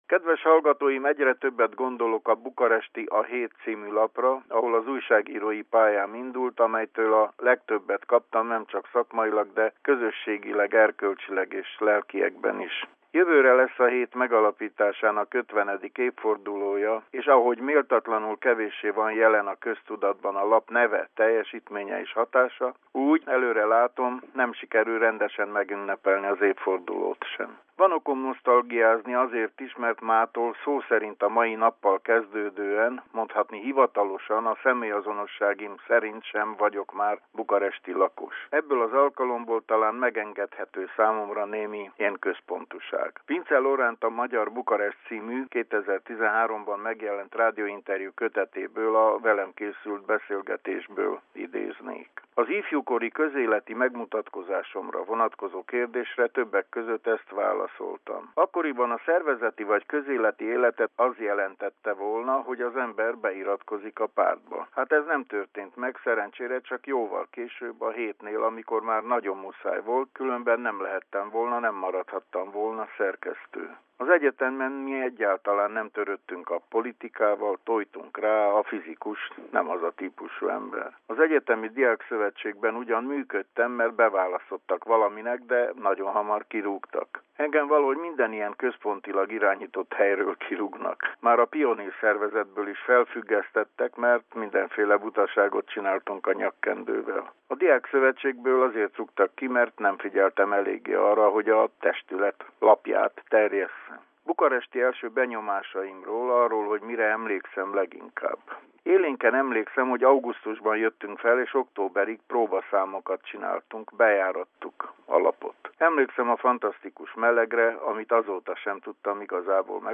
Forrás: Bukaresti Rádió magyar nyelvű adása, Vélemény rovat, 2019. május 9.